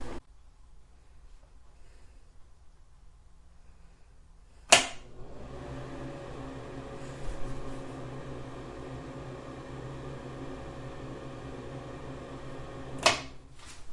浴室里的风扇
Tag: 鼓风机 风扇 吹风机 排气